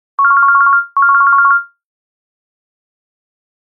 Ring ring tone